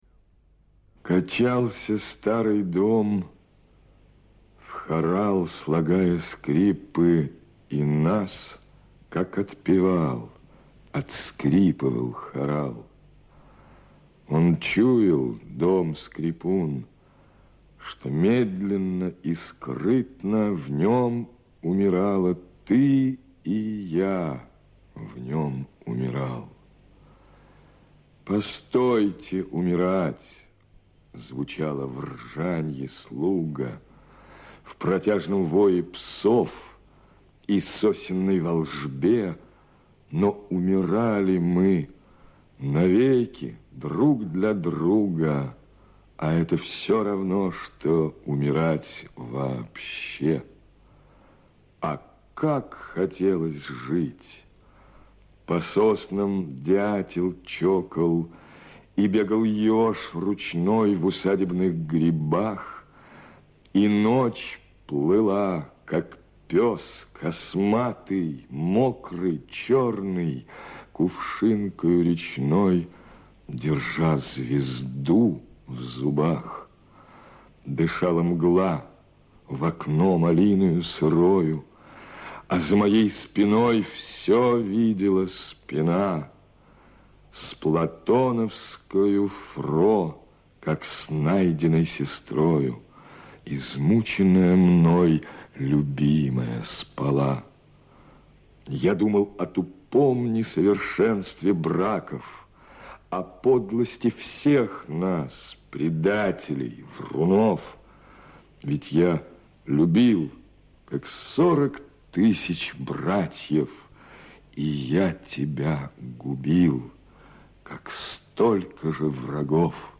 Evtushenko-chitaet-Valentin-Nikulin-Kachalsya-staryy-dom-stih-club-ru.mp3